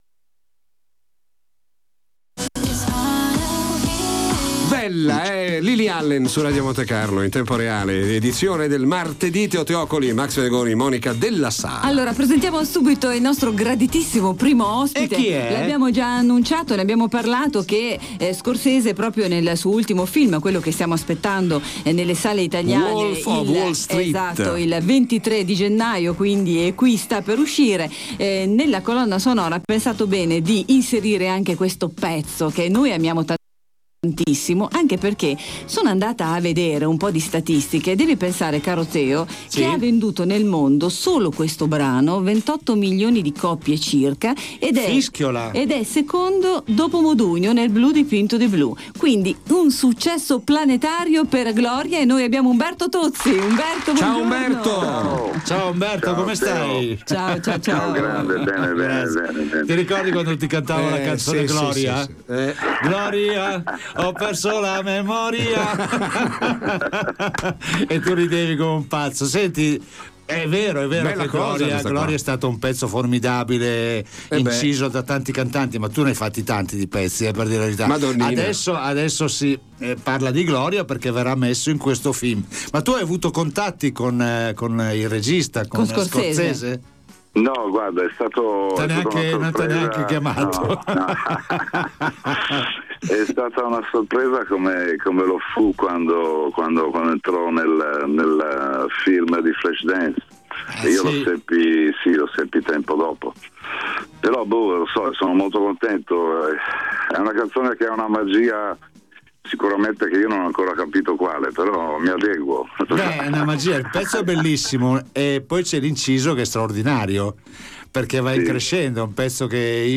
Teo é un battitore libero, si cala nei ruoli più divertenti dando vita ai suoi personaggi: l’incorreggibile Mario Balotelli, l’imbranato cronista sportivo Caccamo, l’ex CT Cesare Maldini, il super tifoso Peo Pericoli, il presidente dell’Inter Massimo Moratti, Adriano Celentano e altri ancora.